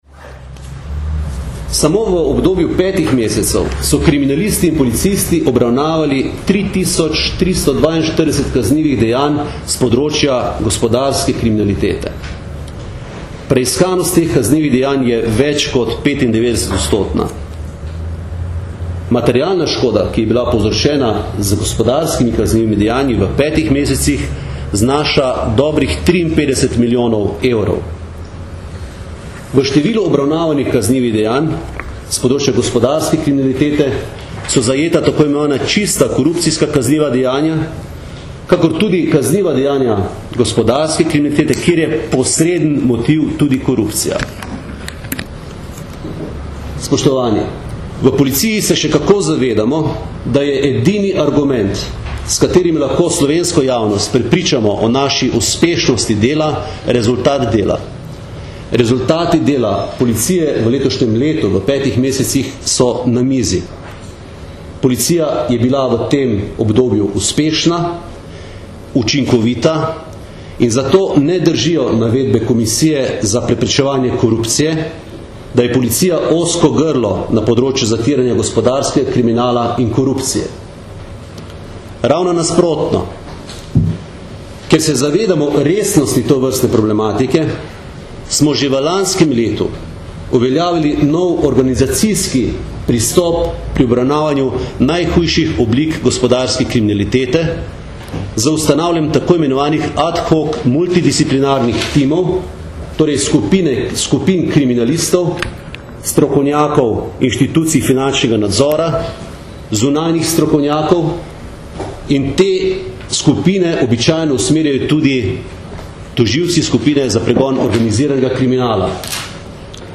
Zvočni posnetek izjave mag. Aleksandra Jevška (mp3)